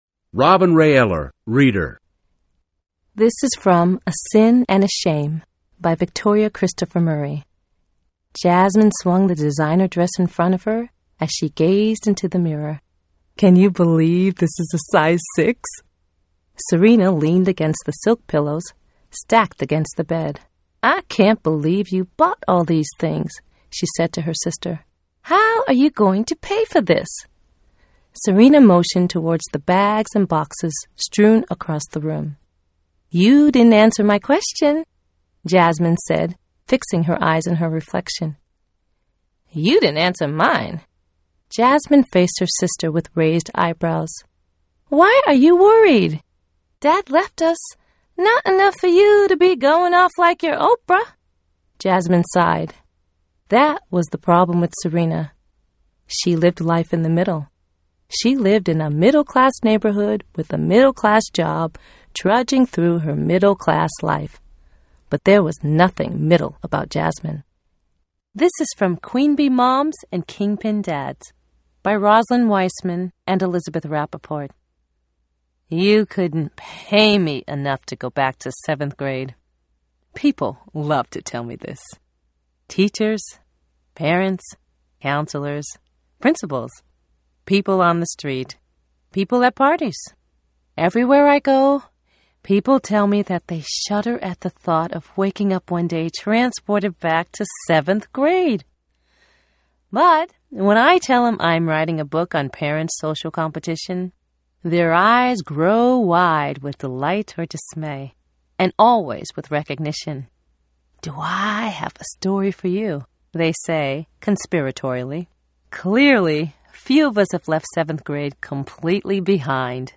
An American English speaker with a confident, clear, soothing, positive and sometimes sassy voice.
Sprechprobe: Industrie (Muttersprache):